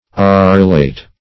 Arillate \Ar"il*late\ Arllated \Ar"l*la`ted\, Ariled \Ar"iled\,